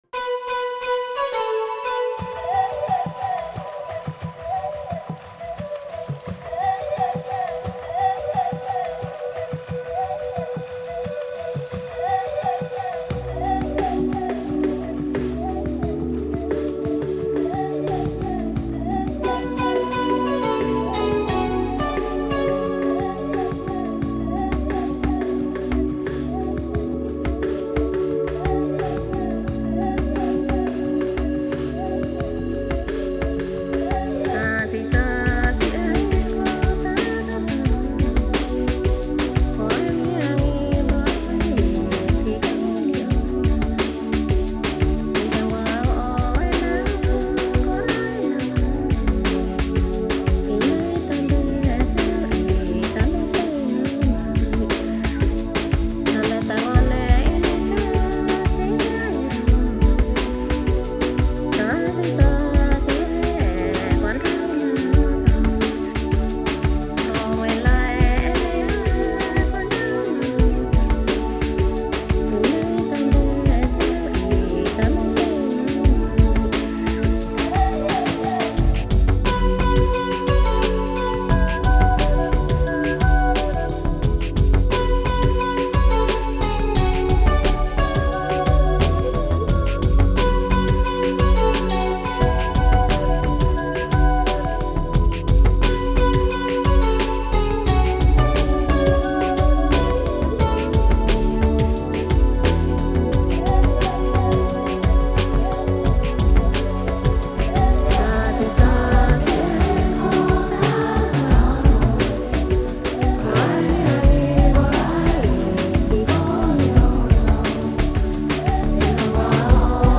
New Age or Smooth Jazz